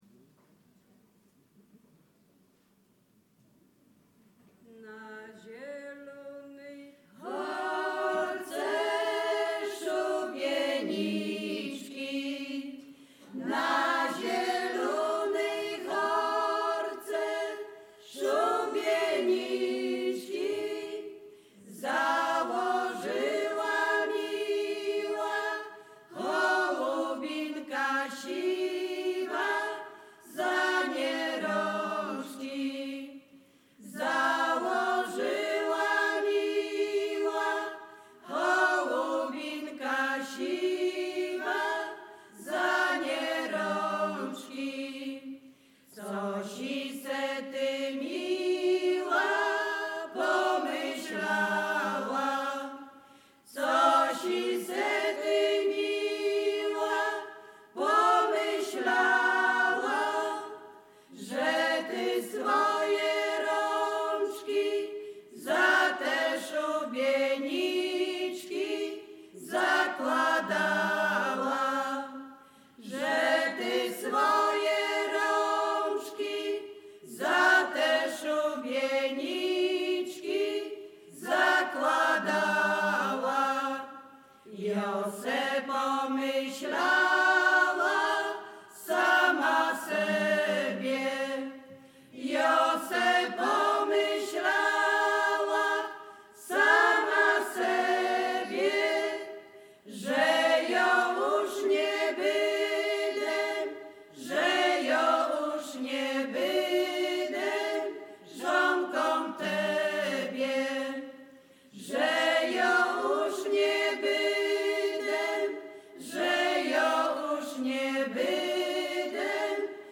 Zespół Dunawiec
Bukowina Rumuńska
liryczne miłosne wojenkowe